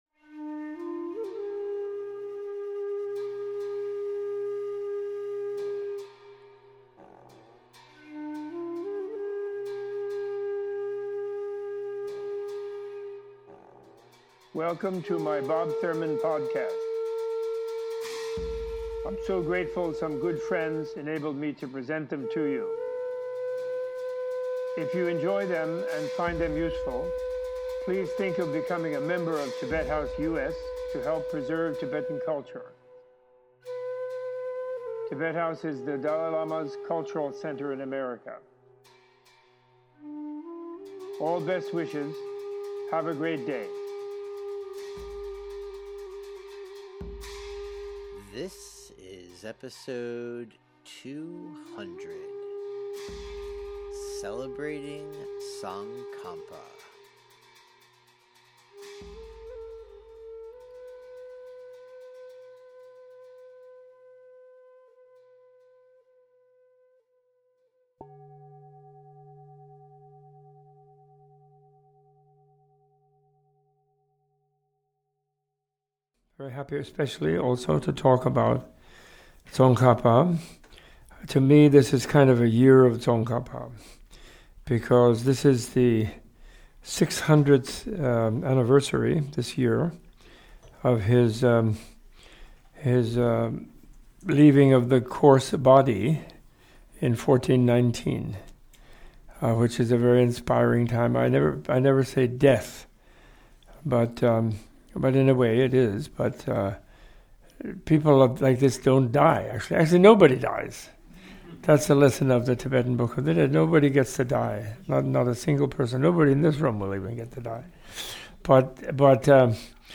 In this special podcast celebrating the 600th anniversary of the enlightenment of Tsongkhapa, Professor Thurman gives an in-depth teaching on his historical importance and impact upon Buddhism, Tibet and philosophy.